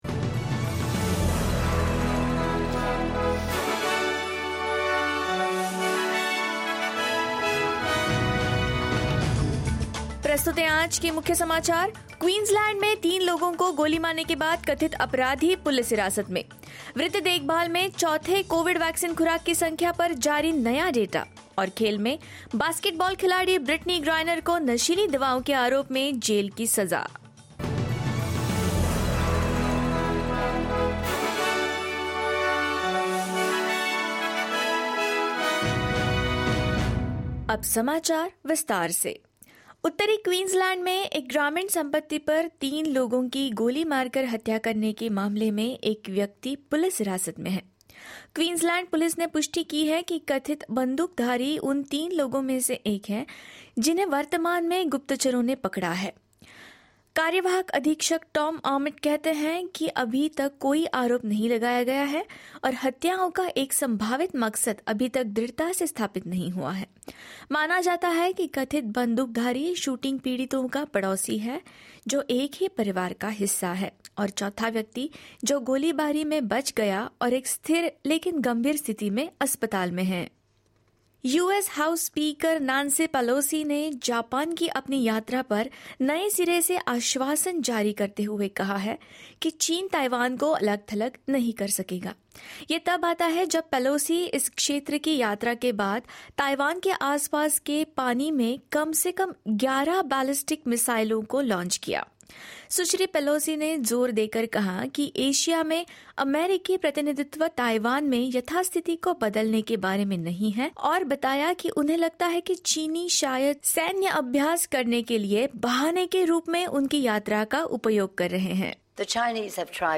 In this latest SBS Hindi bulletin: U-S House Speaker Nancy Pelosi issues fresh reassurances as China recommences military drills;A man remains in custody over the fatal shooting of three people on a rural property in north Queensland; Strong reactions in the US over sentencing of basketballer Brittney Griner on drug charges and more.